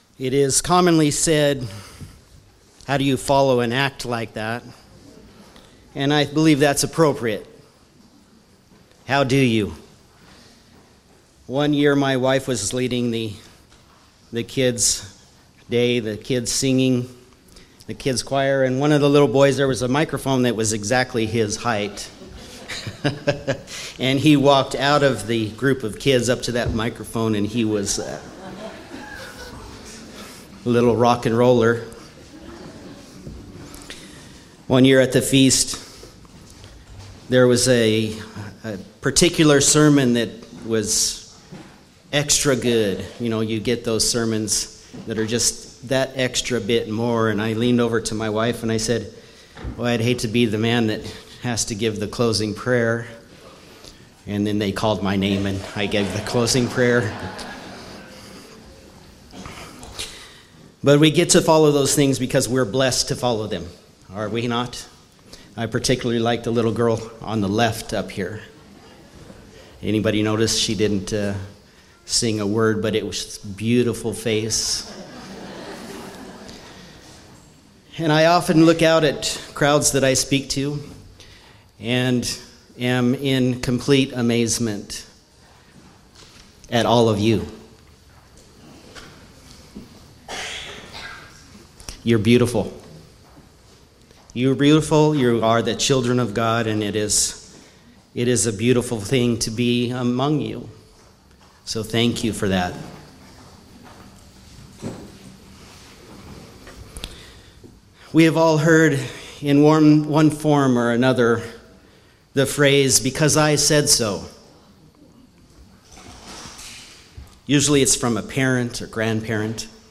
Given in Walnut Creek, Ohio